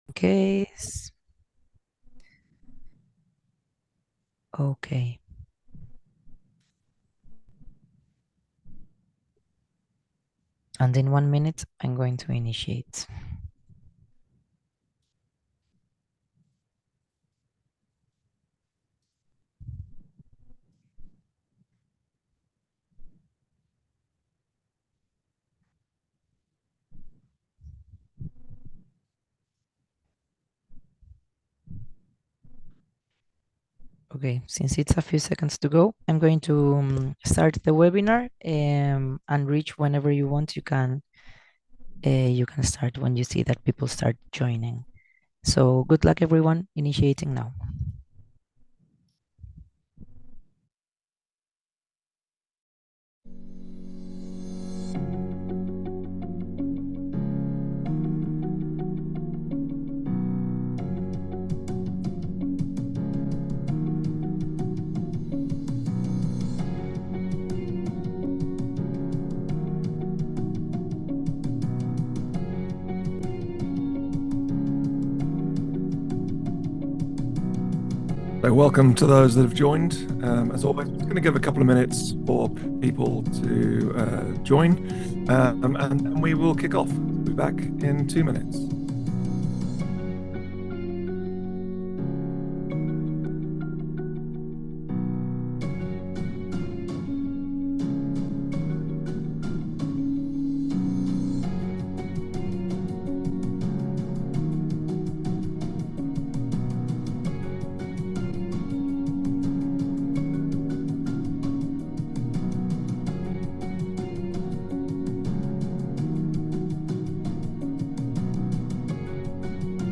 Web Exposure Webinar | Integrity360 & Reflectiz Panel Discussion